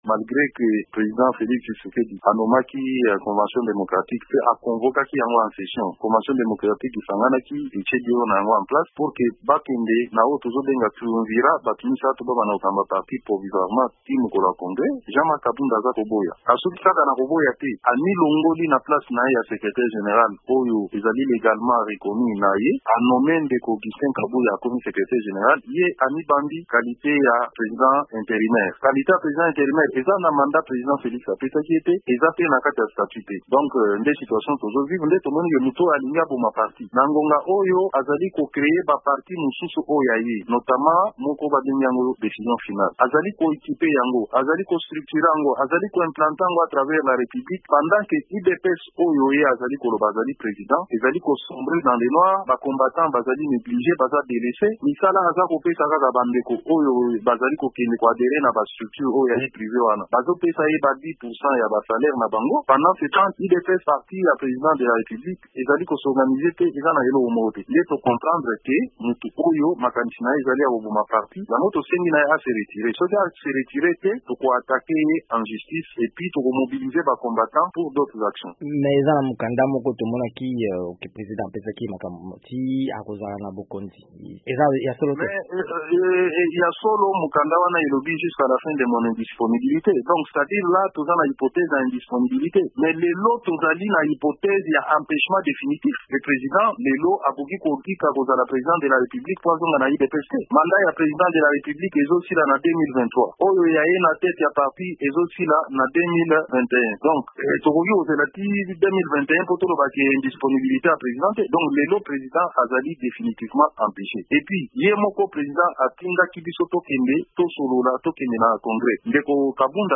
Lelo, lundi 16 mars, nde ultimatum ya bayike na bakambi ya UDPS ezosila. VOA Lingala etunaki moko na bayi "Sauvons l'UDPS", Me Peter Kazadi.